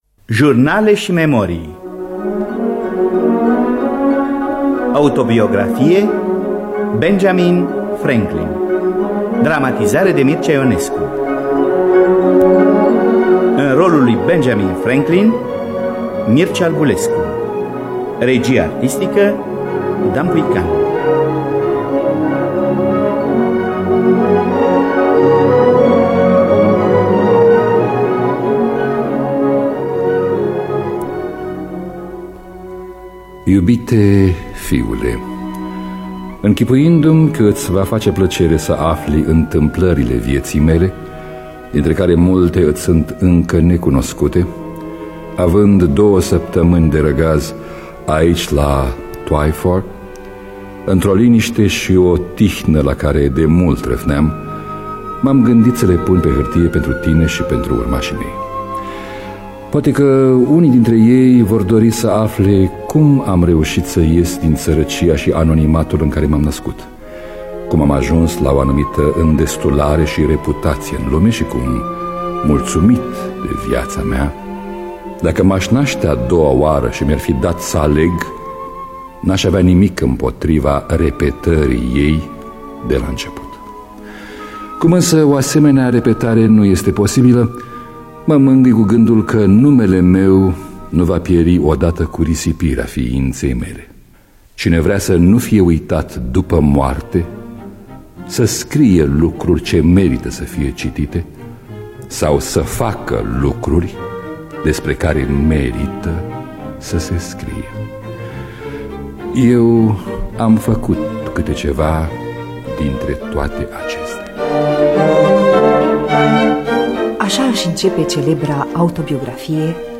Biografii, Memorii: Benjamin Franklin – Autobiografie (1986) – Teatru Radiofonic Online